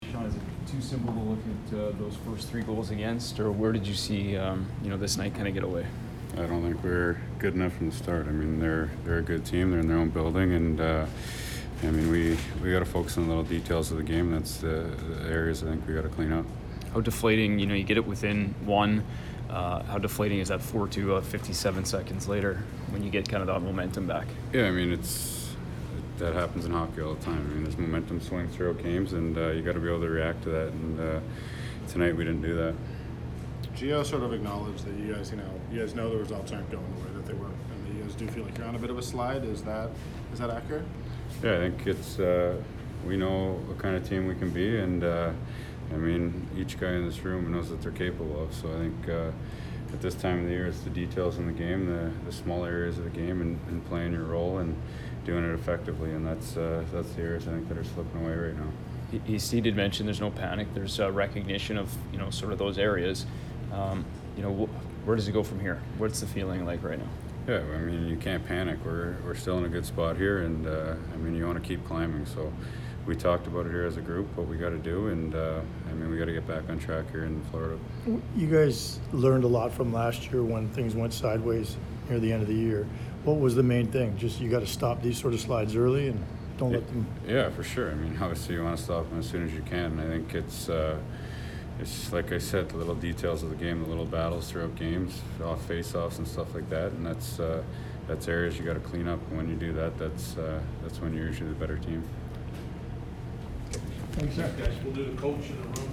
Sean Monahan post-game 2/12